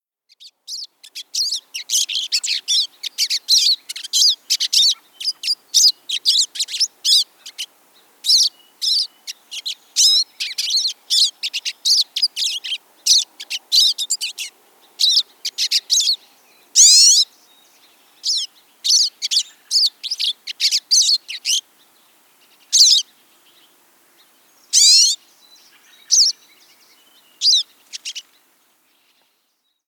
Pine Siskin
How they sound: This bird's most recognizable call is a harsh, upsweeping zreeeet in between shorter calls.